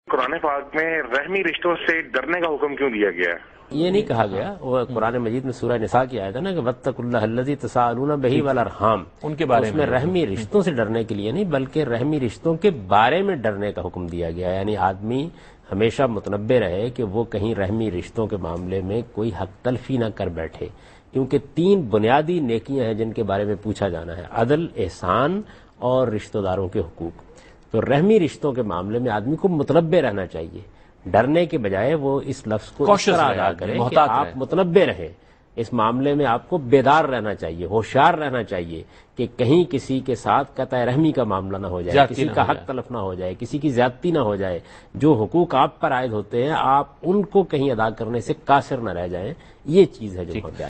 Category: TV Programs / Dunya News / Deen-o-Daanish /
Javed Ahmad Ghamidi answers a question about "The Directive to Fear Blood Relations" in program Deen o Daanish on Dunya News.